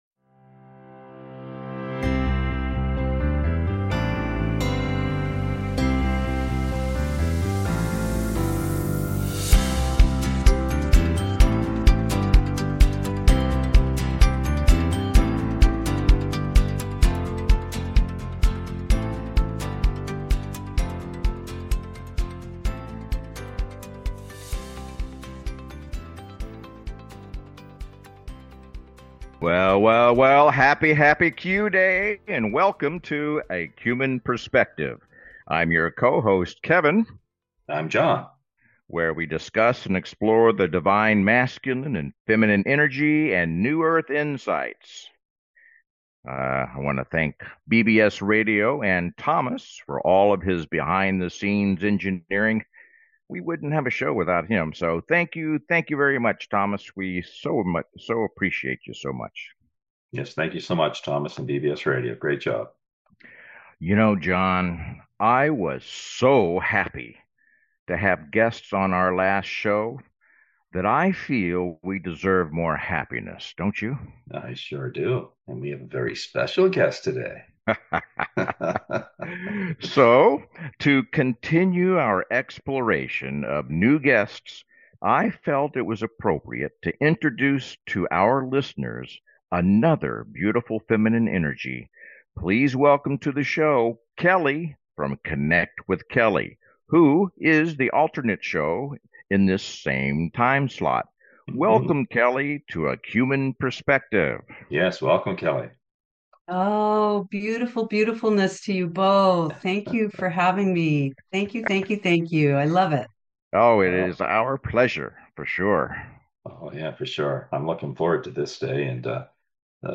Talk Show Episode, Audio Podcast
The show is structured to welcome call-ins and frequently features special guests, offering a diverse range of perspectives.
The show is not just informative but also entertaining, with humor, jokes, and a whole lot of fun being integral parts of the experience.